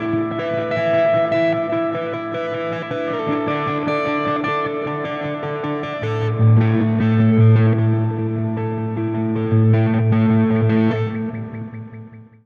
Spaced Out Knoll Electric Guitar Ending.wav